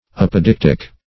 Apodeictic \Ap"o*deic"tic\, Apodictic \Ap`o*dic"tic\,